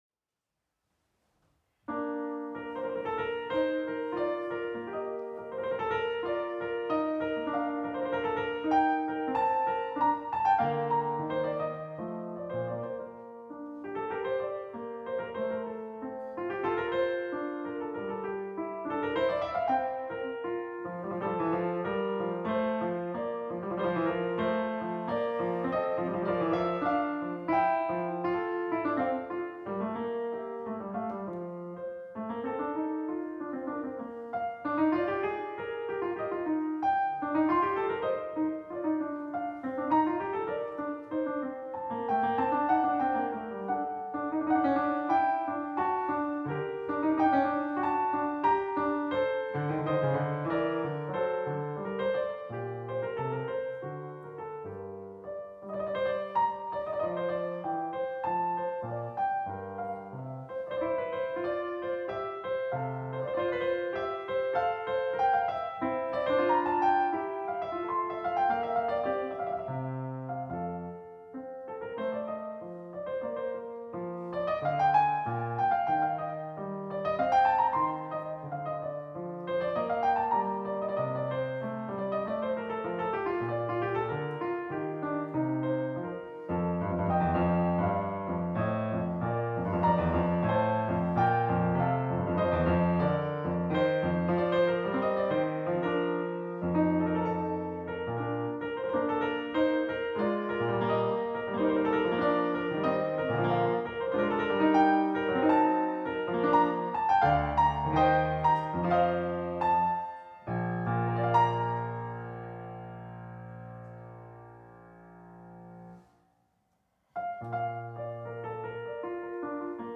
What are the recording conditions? Venue: Bantry House Composition Year